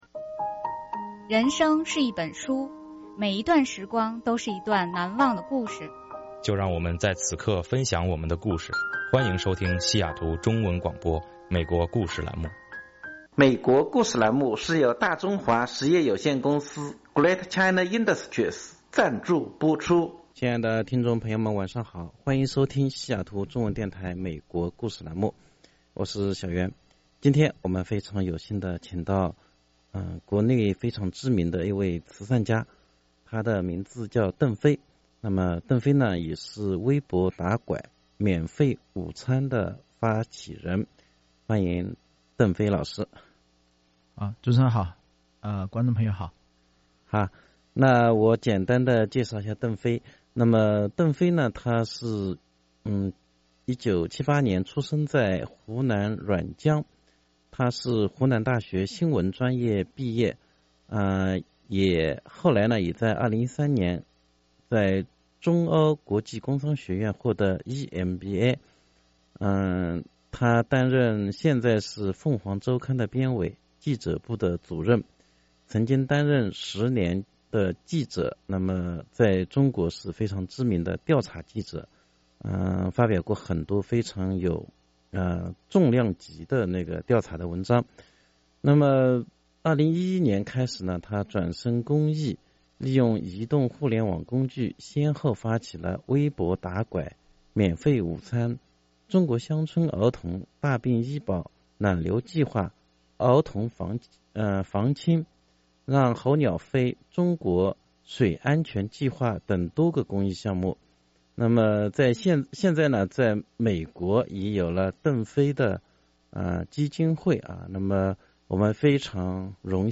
专访“免费午餐”、“微博打拐”公益项目的发起人邓飞
（邓飞接受西雅图中文电台“美国故事”栏目专访）